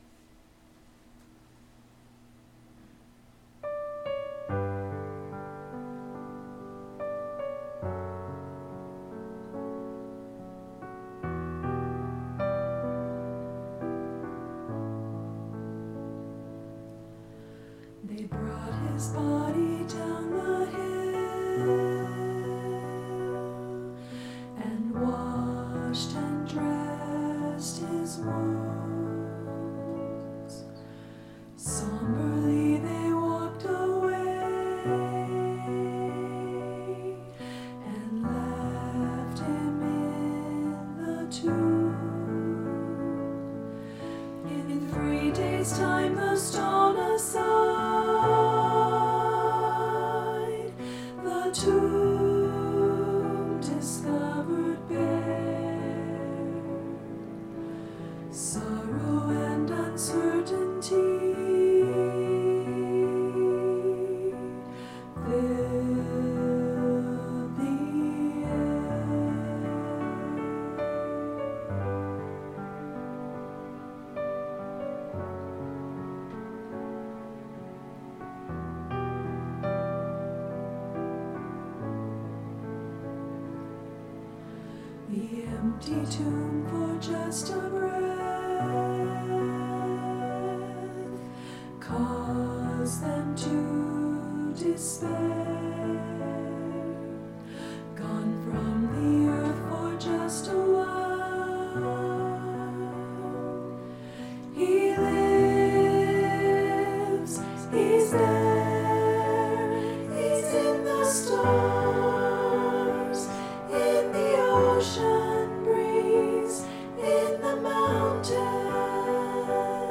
Voicing/Instrumentation: SA , Duet